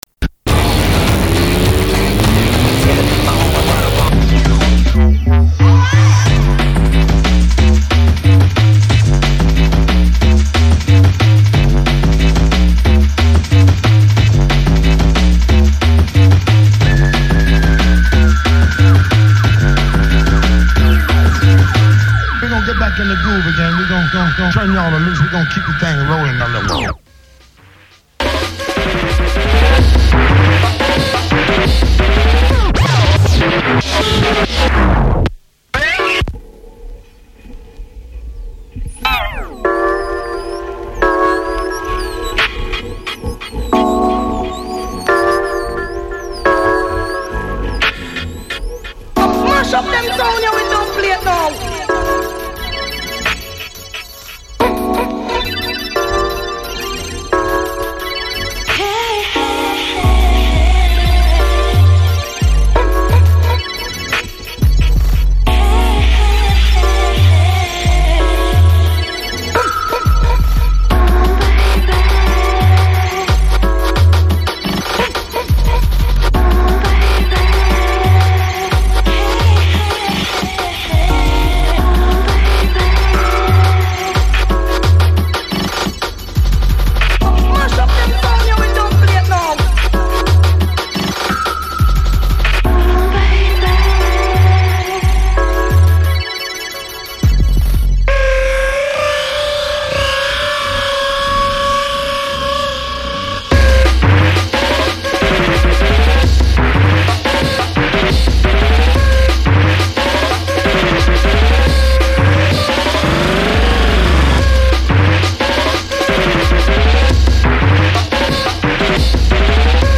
This eclectic mix